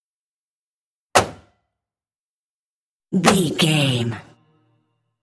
Car hood close
Sound Effects